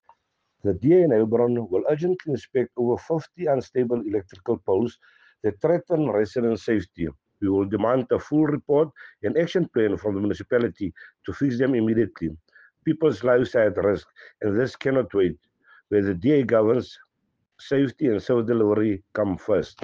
English and Afrikaans soundbites by Cllr Robert Ferendale and